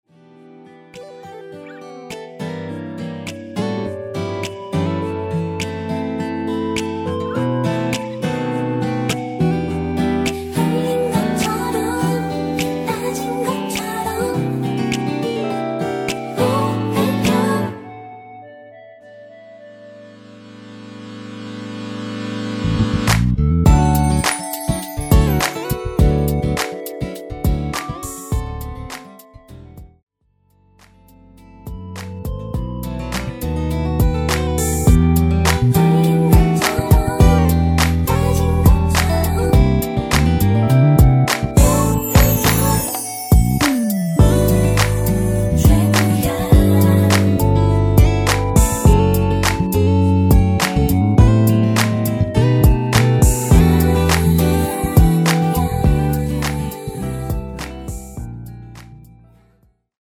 멜로디라인과 코러스가 포함된 MR 입니다.(미리듣기 참조)
앞부분30초, 뒷부분30초씩 편집해서 올려 드리고 있습니다.
중간에 음이 끈어지고 다시 나오는 이유는